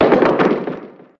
diceend.mp3